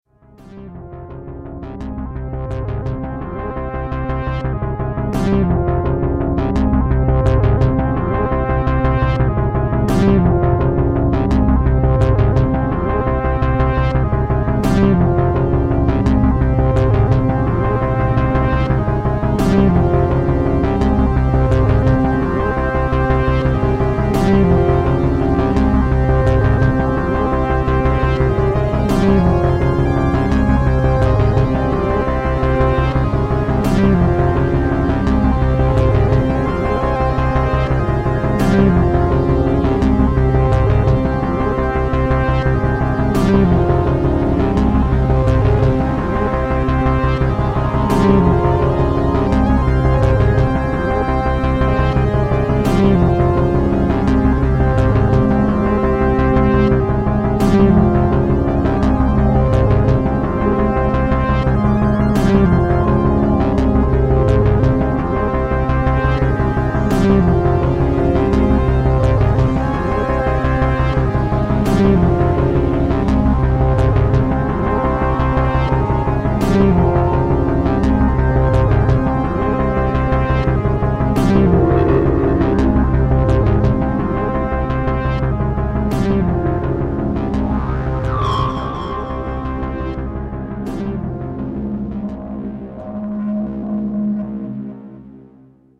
I also like that you can layer unlimited over the sounds with any sound you want from the machine, ofcourse its just re-sampling but it works. Just recorded this little tryout straight into the computer from the thing
still sounds like legowelt  smile